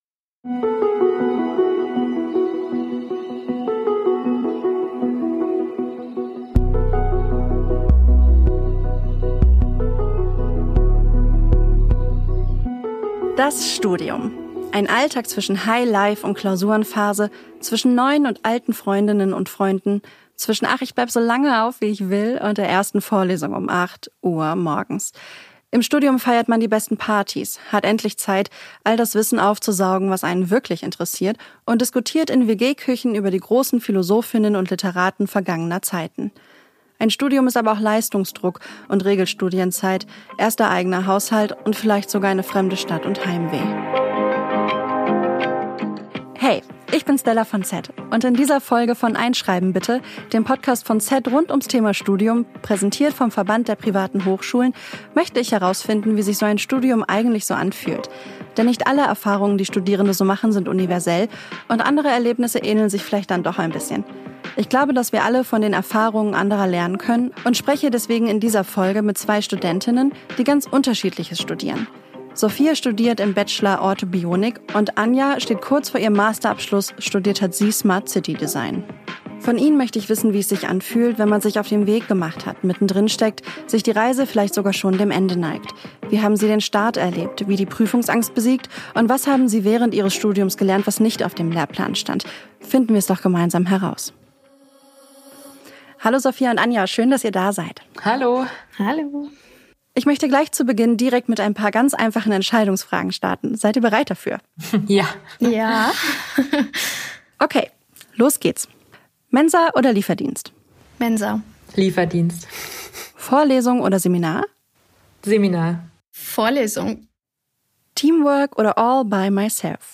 Deswegen sprechen wir in dieser Folge mit zwei Studentinnen